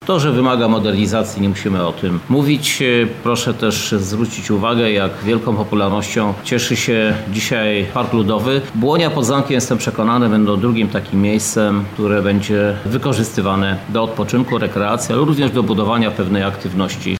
– Chcemy, by ta przestrzeń służyła mieszkańcom Lublina – mówi prezydent miasta Krzysztof Żuk.